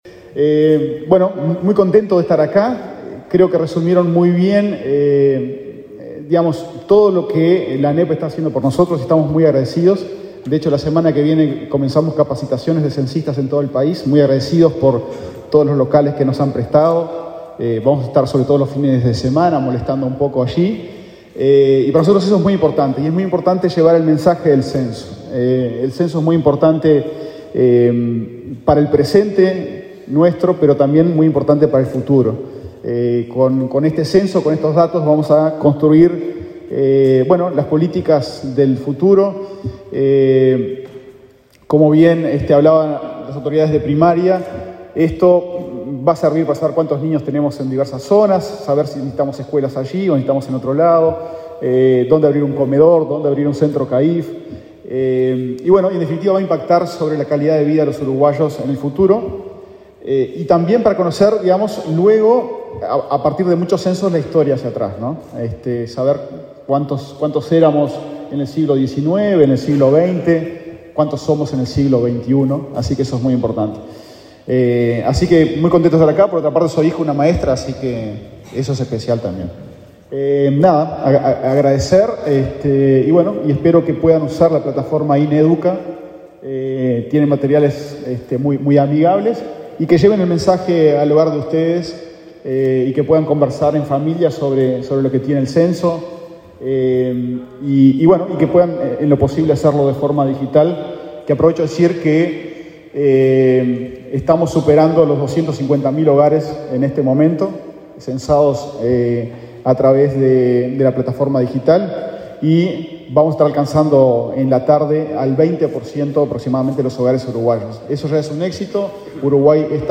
Palabras de autoridades en lanzamiento del INE y la ANEP
Palabras de autoridades en lanzamiento del INE y la ANEP 09/05/2023 Compartir Facebook X Copiar enlace WhatsApp LinkedIn El director del INE, Diego Aboal, y el consejero de la ANEP, Juan Gabito Zóboli, participaron del lanzamiento del Día Nacional del Censo 2023 en la educación, realizado este martes 9 en Montevideo.